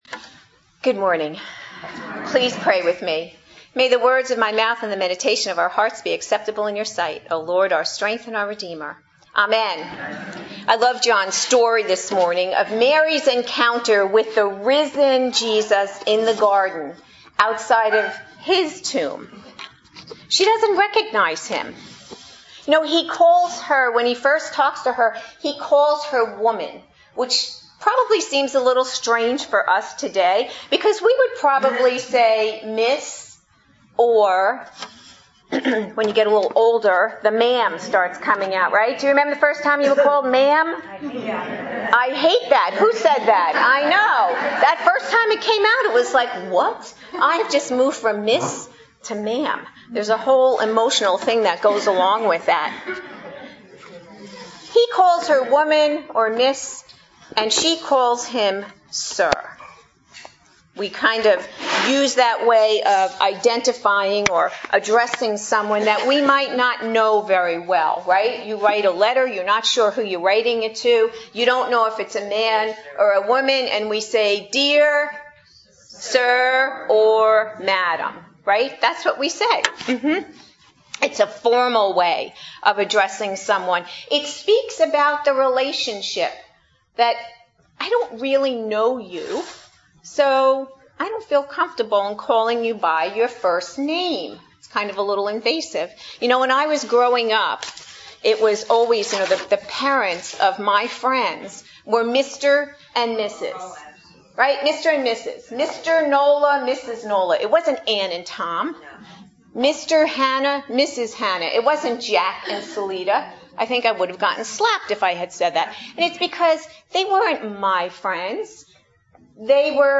Adult Sermons